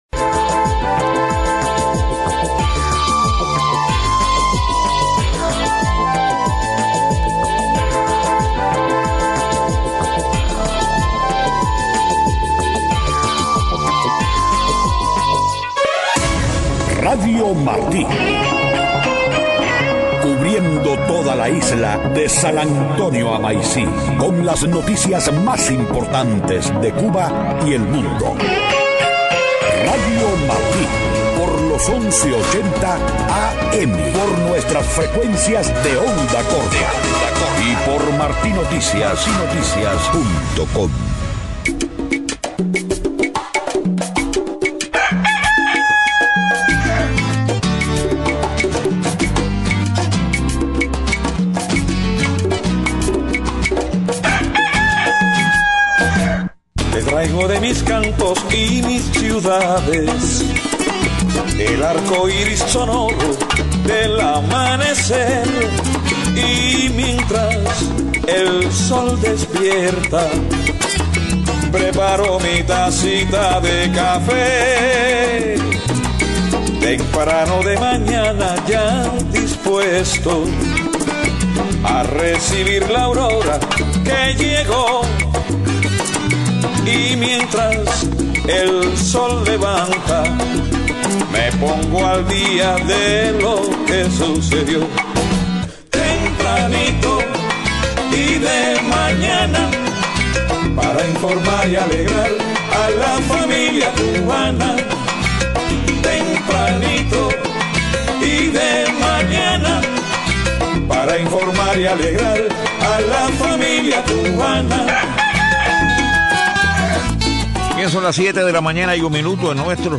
7:00 a.m Noticias: Canciller español dice que la perspectiva sobre el Gobierno de Cuba ha cambiado en el mundo. Genera polémica el anuncio del presidente Obama de acciones ejecutivas de inmigración.